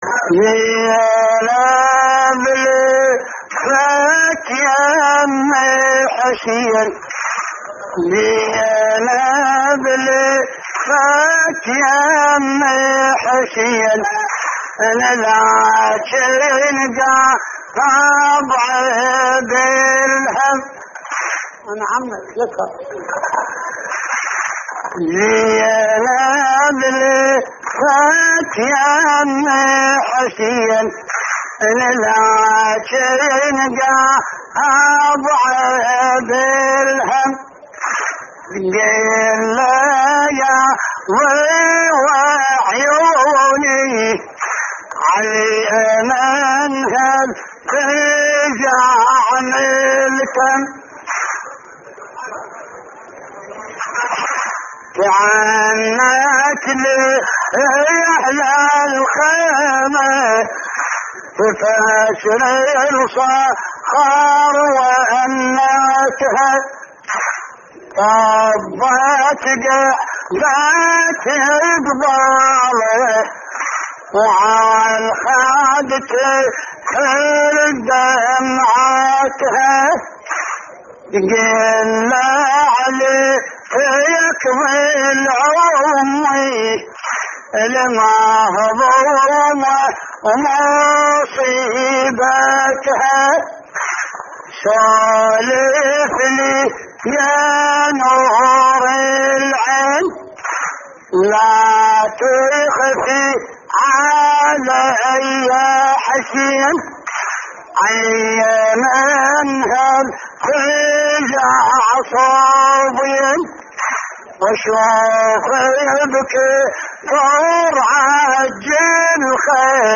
تحميل : زينب لفت يم حسين / الرادود حمزة الصغير / اللطميات الحسينية / موقع يا حسين